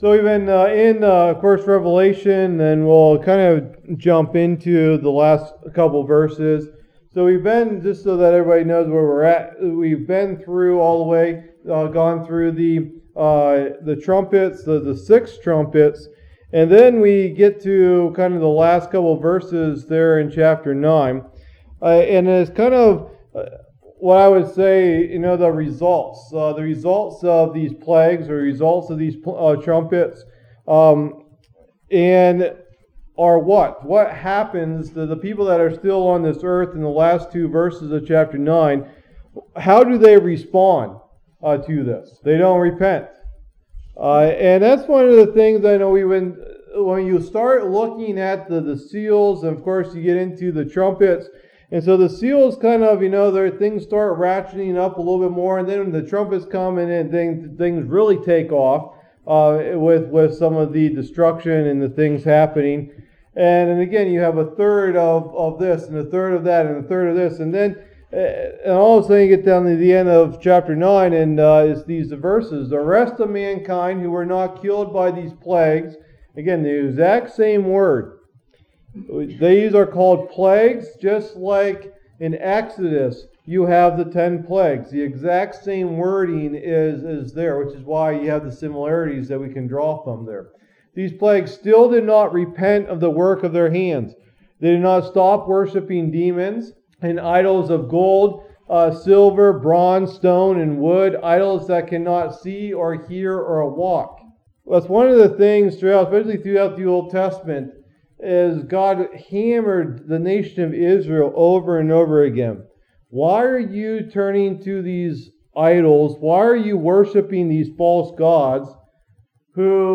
Message #21 in the "Book of Revelation" teaching series